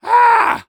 Yell.wav